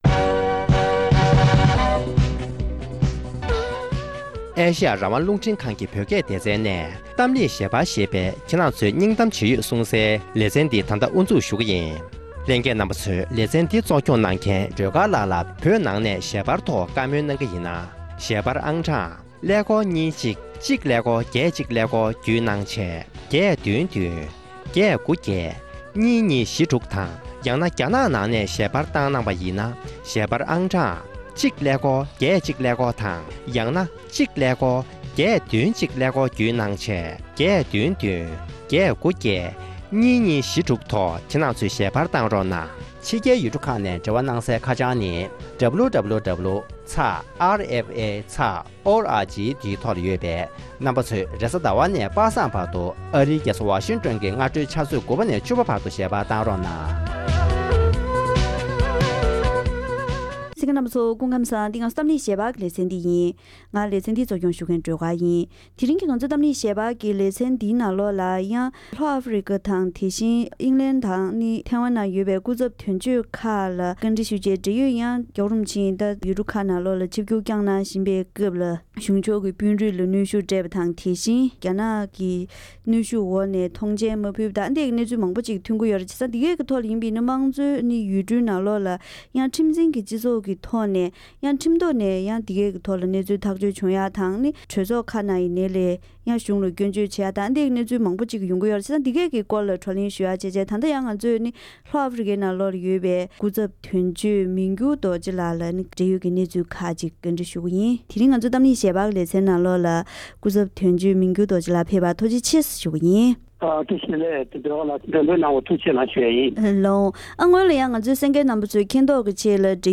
༸གོང་ས་མཆོག་ཡུལ་གྲུ་མང་པོའི་ནང་ཆིབས་བསྒྱུར་བསྐྱངས་སྐབས་རྒྱ་གཞུང་གིས་བར་ཆད་བཟོད་པའི་ལས་དོན་འབྲེལ་ཡོད་དང་བཀའ་མོལ་ཞུས་པ།